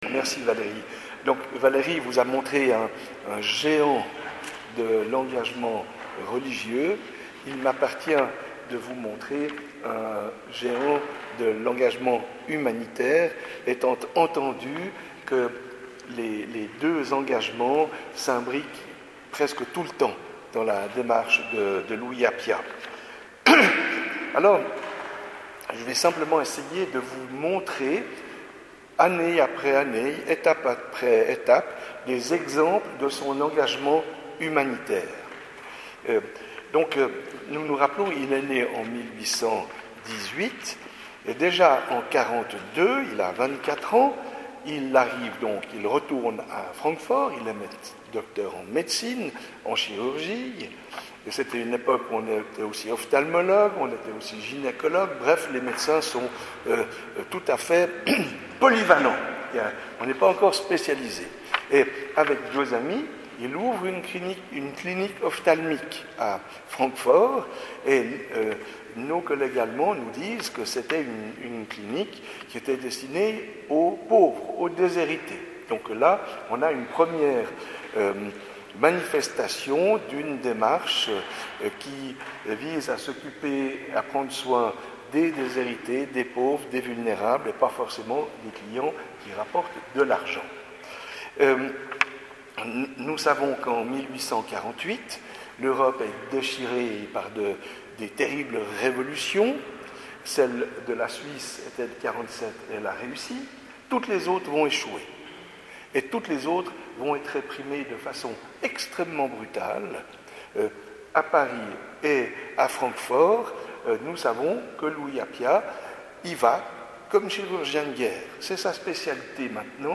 Enregistrements réalisés le mardi 17 mai 2022 au Temple de la Madeleine